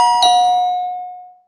Doorbell Ring
A classic two-tone doorbell chime ringing clearly in a quiet residential hallway
doorbell-ring.mp3